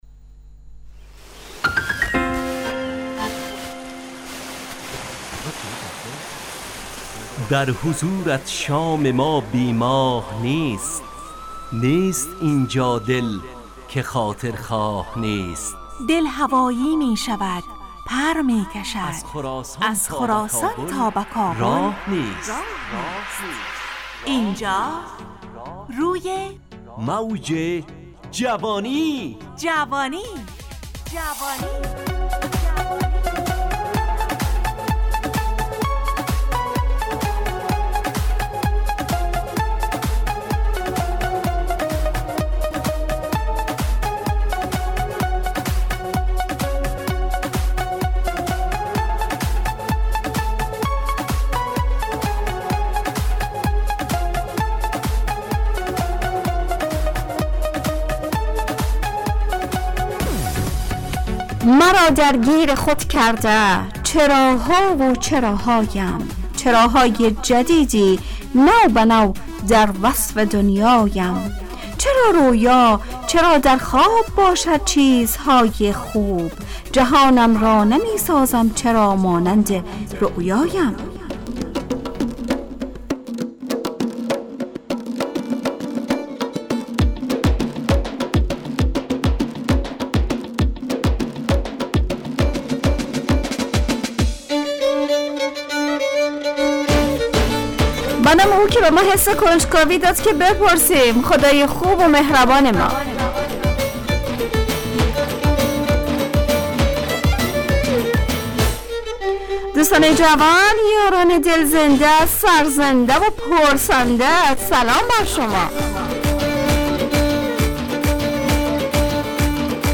همراه با ترانه و موسیقی مدت برنامه 70 دقیقه .